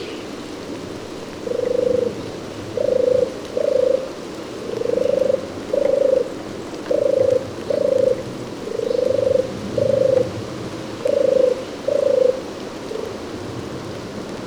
PFR03875, 100509, European Turtle Dove Streptopelia turtur, song, Grosssteinberg, Germany
I found four singing European Turtle Doves Streptopelia turtur but once
again the conditions for sound recordings were not the best.
The doves were astonishingly shy, so I couldn`t approach them closer then